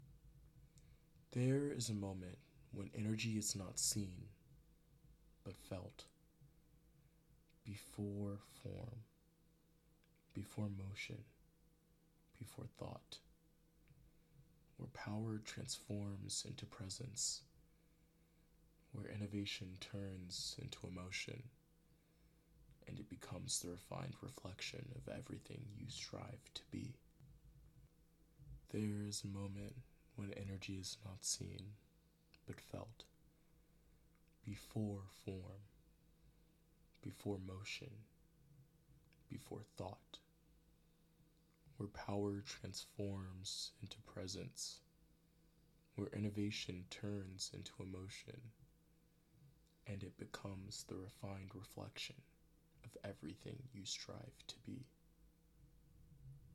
Voice Over
Book / Contact Voiceover Commercial Animation Singing Commercial Sample Commercial voice over trackmp3.mp3 --:-- Download Audio
Commercial voice over trackmp3.mp3